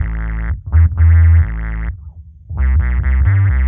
Index of /musicradar/dub-designer-samples/130bpm/Bass
DD_JBassFX_130A.wav